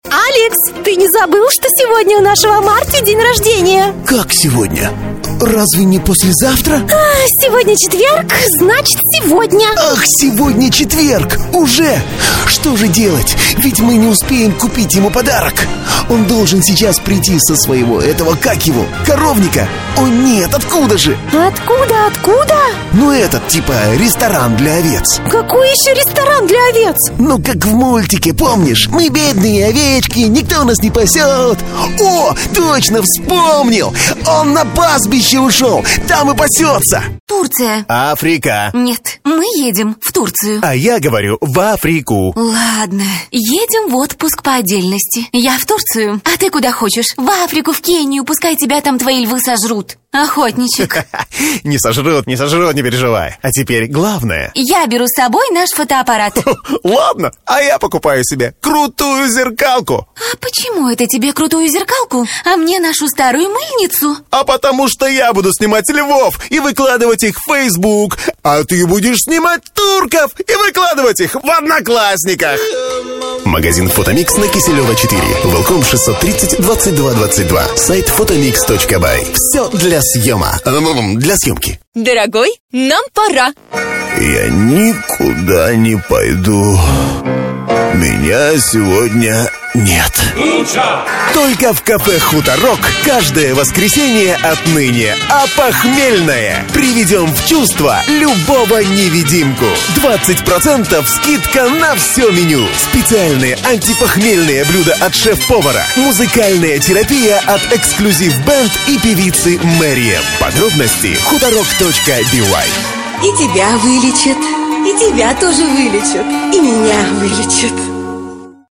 Бархатно. Динамично. Позитивно. РРРРРекоРРРдно. Игрово.
микрофон: Neumann TLM 103,предусилитель: dbx 376,конвертор: M-Audio Delta 44.